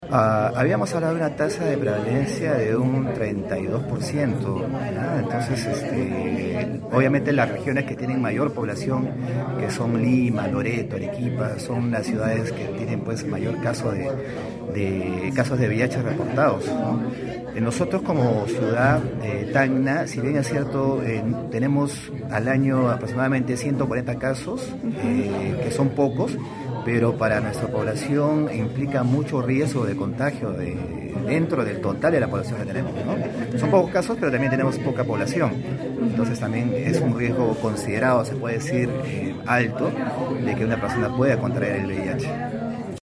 En el marco del Día Mundial de Lucha contra el Sida, que se conmemora el 1 de diciembre, la Dirección Regional de Salud de Tacna (DIRESA Tacna) realizó una conferencia de prensa donde se destacó que el 90% de la población diagnosticada con VIH en Tacna ahora tiene acceso a tratamientos antirretrovirales gratuitos, sin importar su género, raza o procedencia.